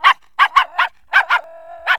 Unused audio of a little dog barking in ZV
Zombieville1997-Unused_little_dog_barking_DOG.ogg